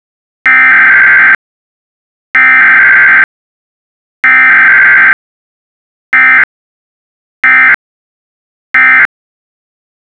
eas-same-encoder - A Python script that generates valid EAS SAME messages.